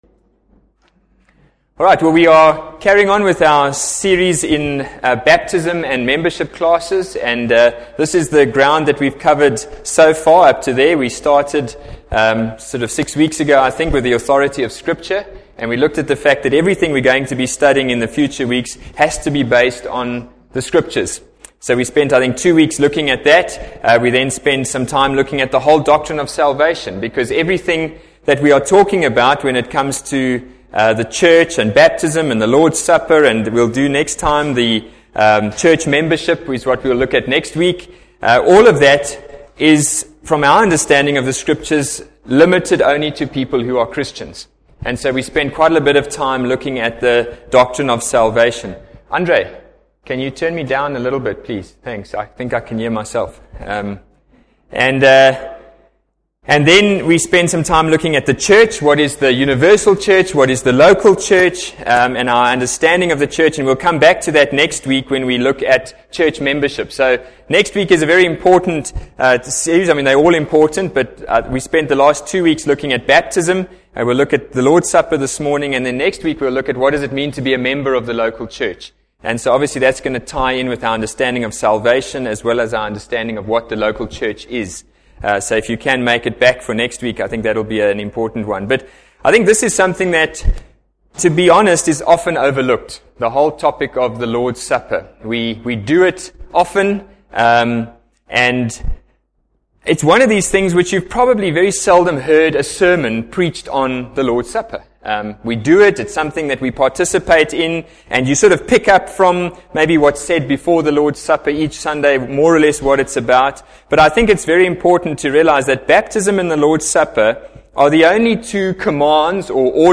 Adult Bible Class  - The Lords Supper.mp3